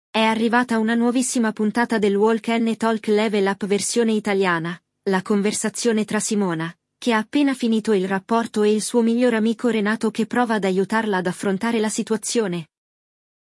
È arrivata una nuovissima puntata del Walk ‘n’ Talk Level Up versione italiana, la conversazione tra Simona, che ha appena finito il rapporto e il suo miglior amico Renato che prova ad aiutarla ad affrontare la situazione!